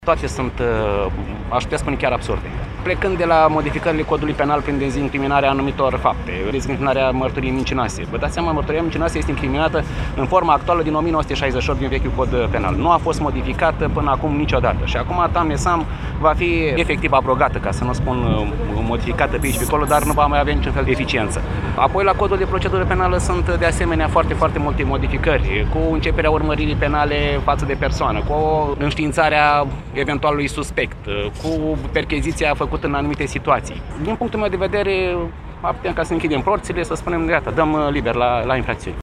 Florin Cășuneanu, procuror general la Curtea de Apel Iași, a declarat că prin aceste modificări se dă liber la comiterea de infracțiuni: